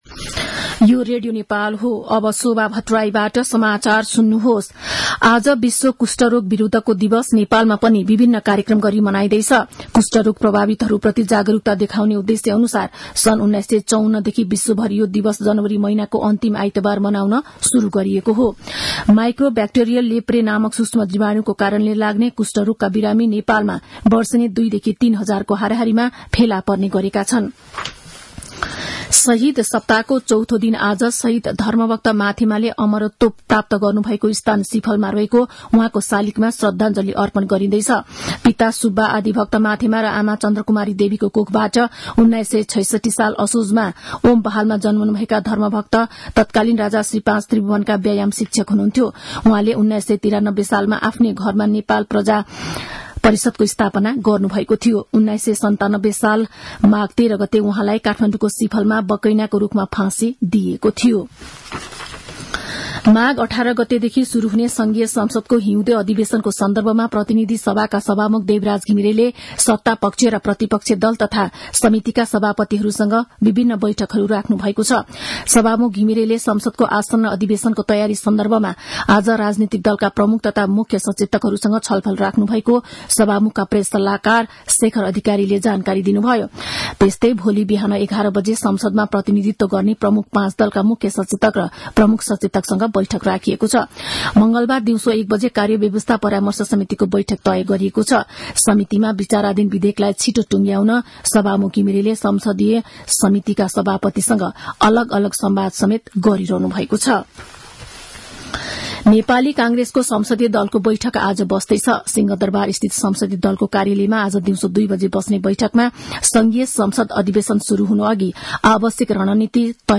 मध्यान्ह १२ बजेको नेपाली समाचार : १४ माघ , २०८१